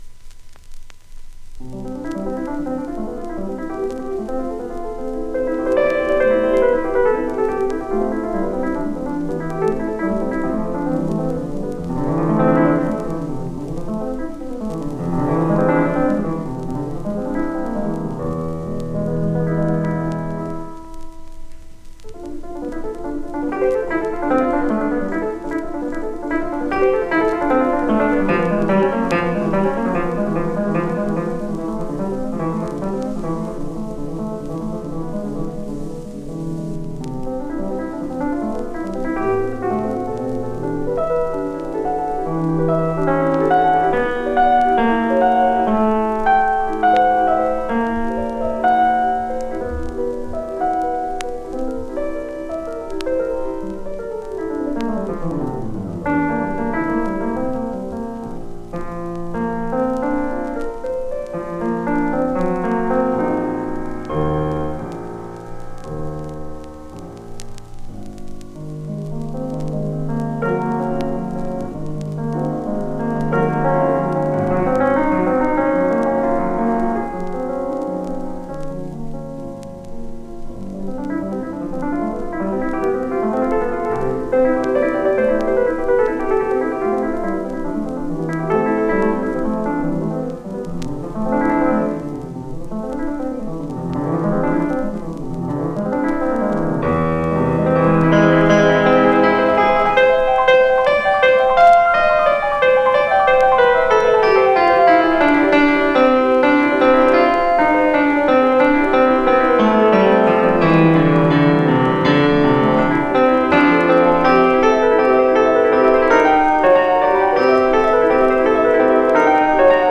musique streaming listening live